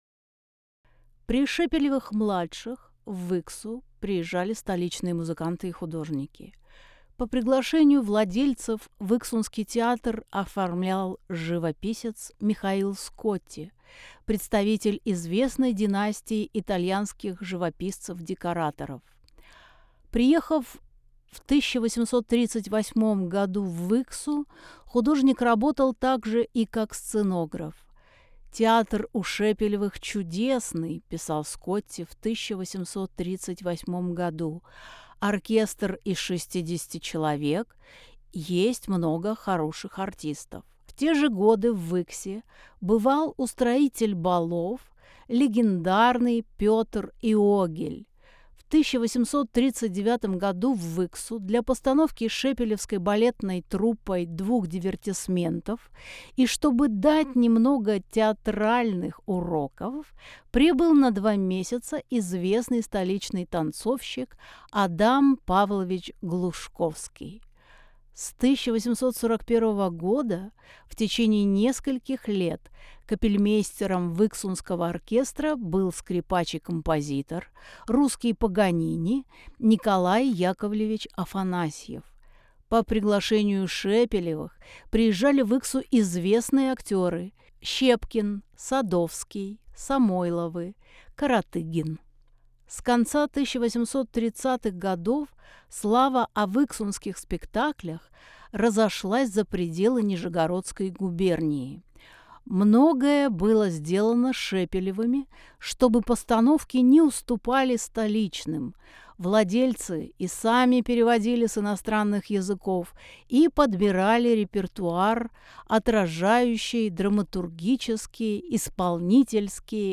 Тифлокомментарии к экспонатам выставки
Аудиогид. 2 этаж. 1 зал. Портреты Адама Глушковского и Михаила Щепкина Аудиогид. 2 этаж. 2 зал.